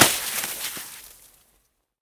snowhit.ogg